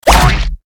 AA_drop_boat_cog.ogg